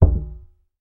Звуки бочки
Мощный удар по бочке